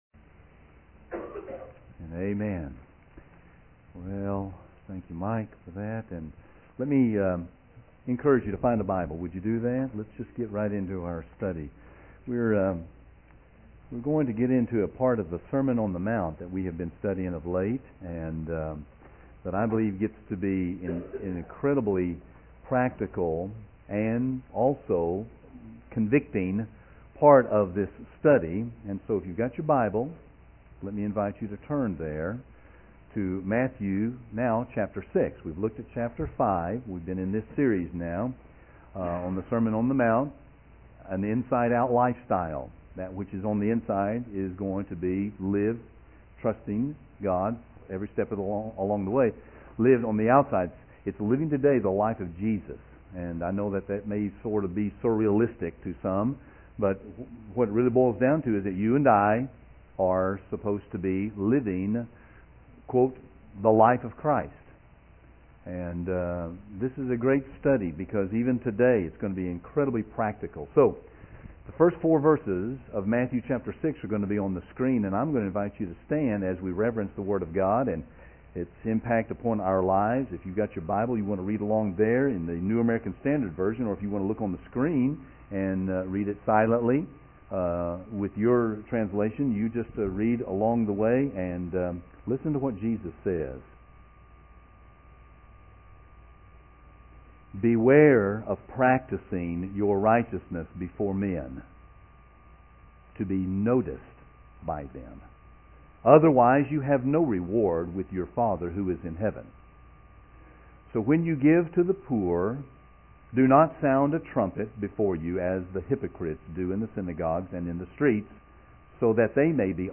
A study through The Sermon On The Mount Matthew 5-7